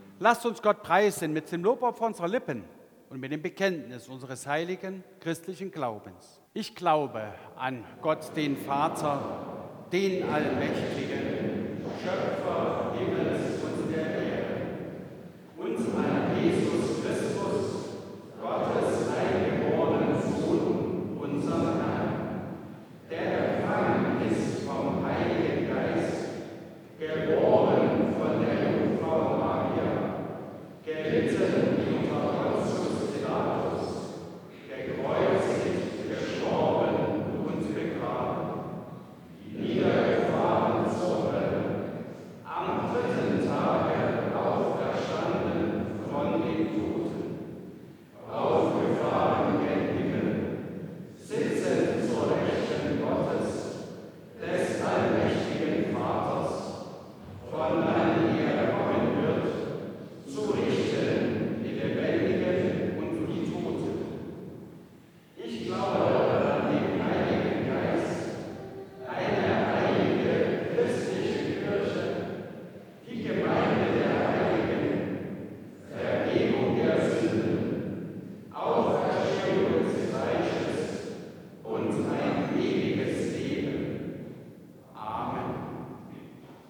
9. Apostolisches Glaubensbekenntnis Ev.-Luth. St. Johannesgemeinde Zwickau-Planitz
Audiomitschnitt unseres Gottesdienstes am Palmsonntag 2025.